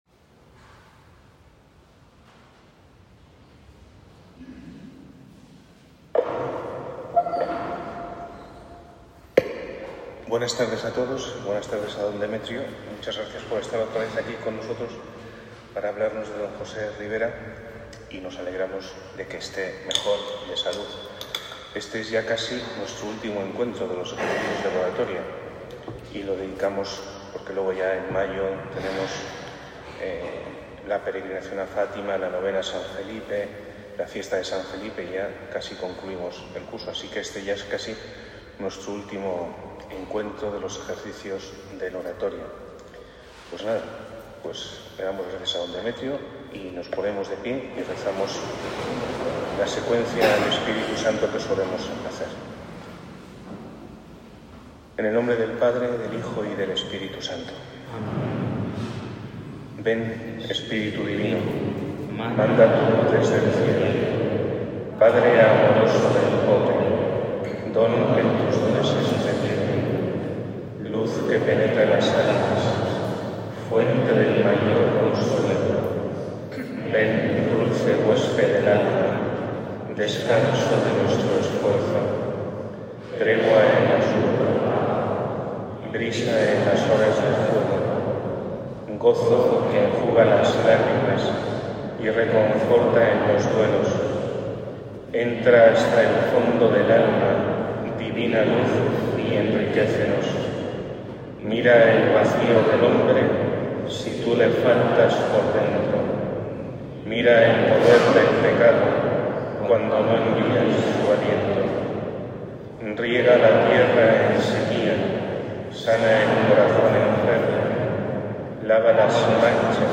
Venerable José Rivera - Vida y doctrina IV Mons. Demetrio Fernández González, Obispo emérito de Córdoba Ejercicio de los Sábados Cuarta charla de Mons. Demetrio Fernández sobre la vida y doctrina del venerable don José Rivera.